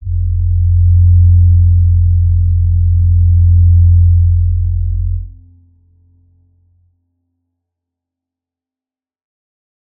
G_Crystal-F2-pp.wav